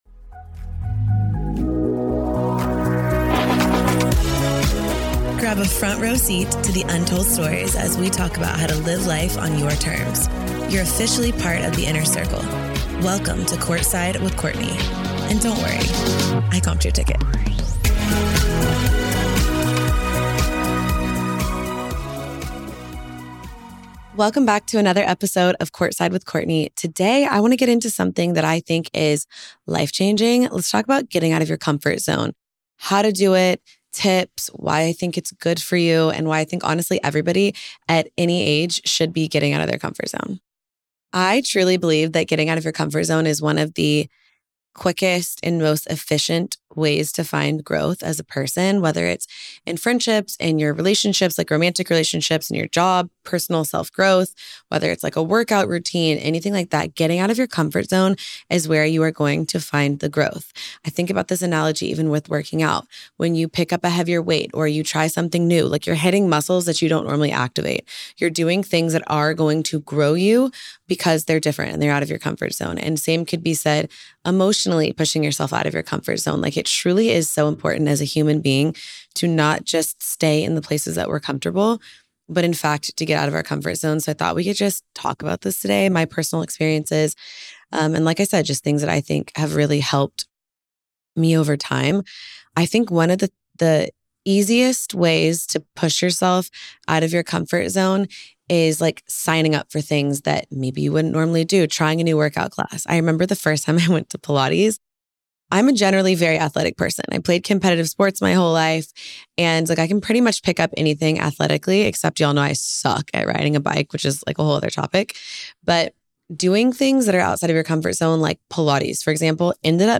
solo episode